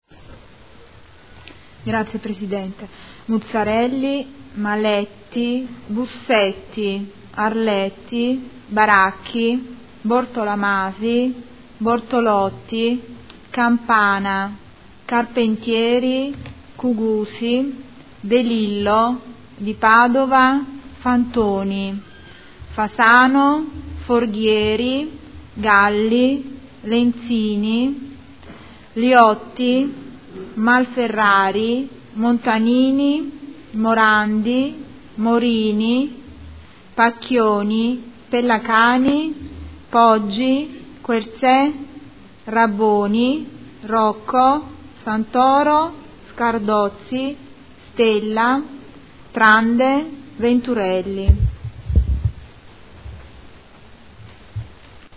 Seduta del 25/09/2014. Appello